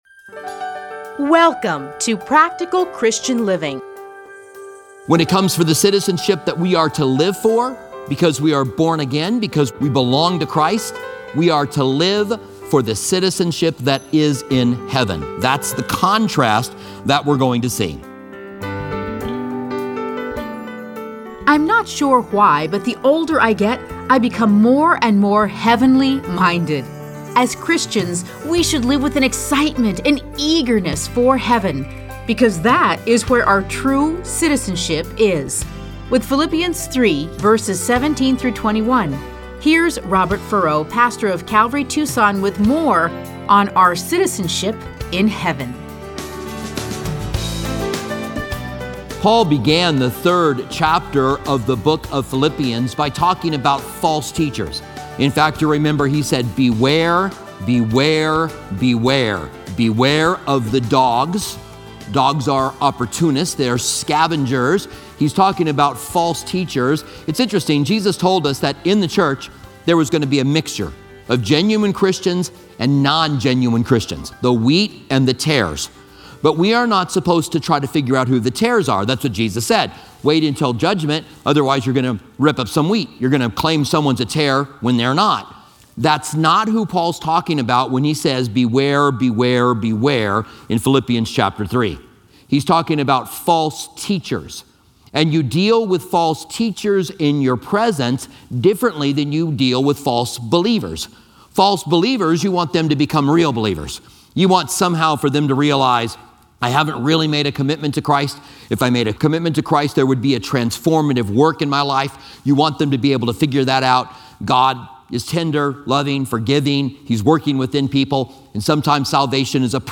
Listen to a teaching from A Study in Philippians 3:17-21.